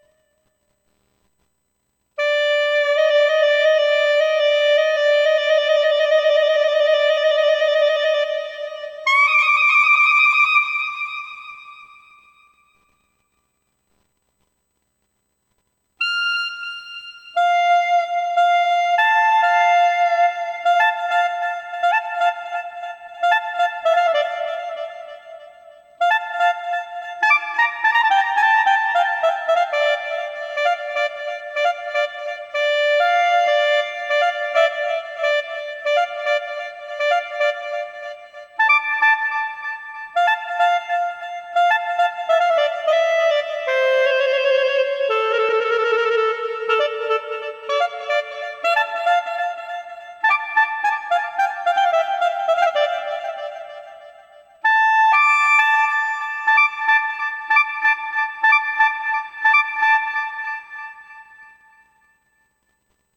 יללה בקלרינט בsx600
תגדיר את הצליל למצב מונופוני (כלומר שאפשר לנגן בו זמנית רק צליל אחד) ואז אתה לוחץ על הקליד המבוקש ומשחק עם הצליל הצמוד אליו בטרילים זה בקליד הכי צמוד אליו והבכי זה הצליל המבוקש + הצליל הבא באקורד בהחלקה מהירה